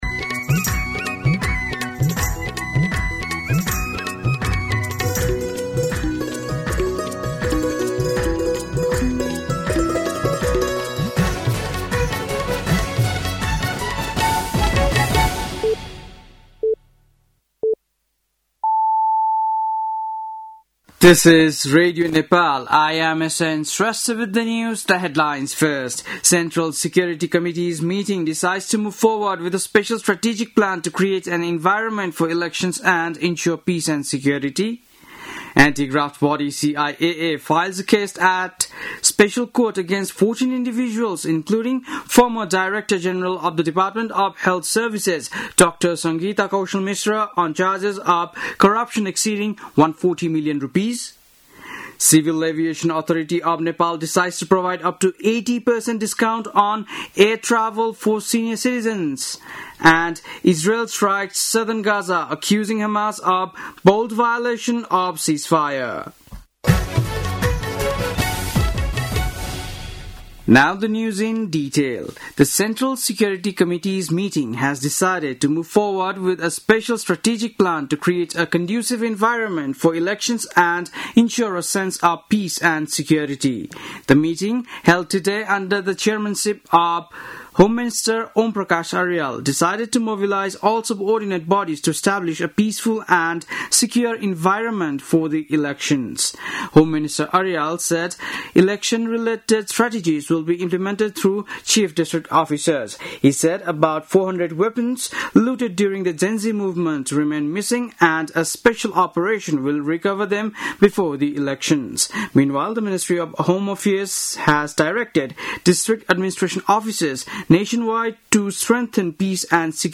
बेलुकी ८ बजेको अङ्ग्रेजी समाचार : २ कार्तिक , २०८२
8.-pm-english-news-.mp3